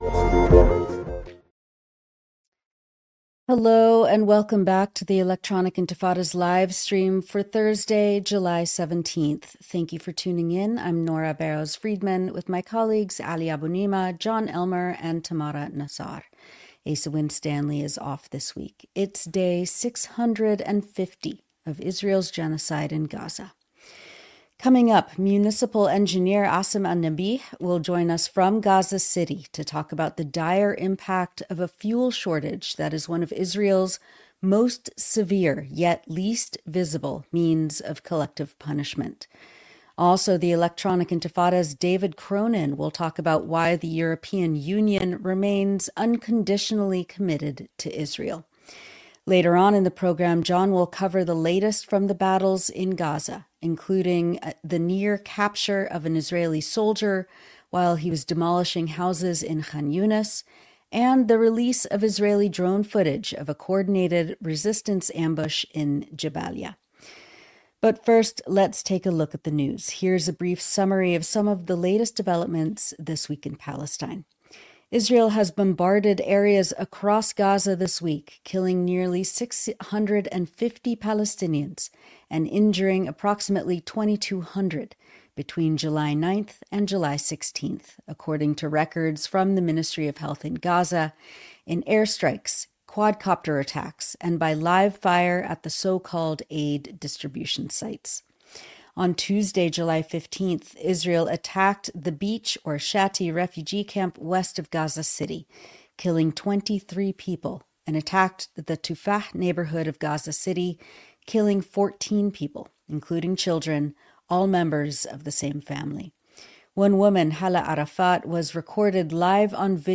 Livestream: Love and care during a genocide